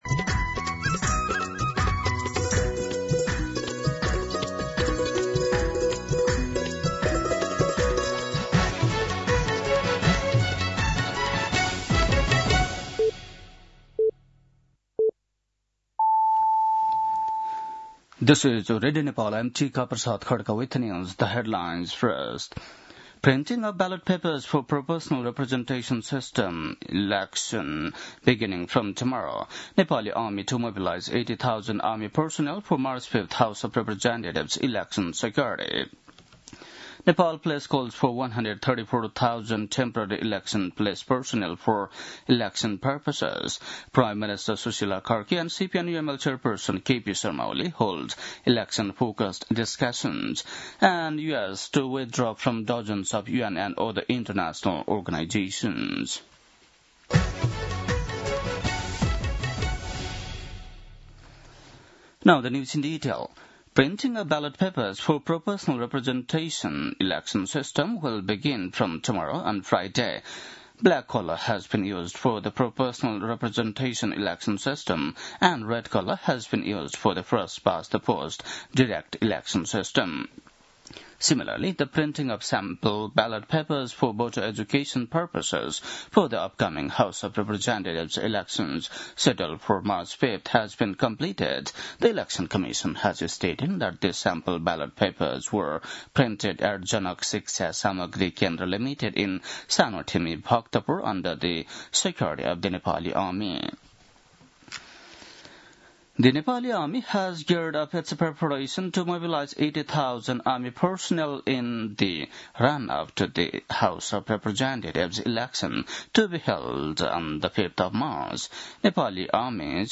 बेलुकी ८ बजेको अङ्ग्रेजी समाचार : २४ पुष , २०८२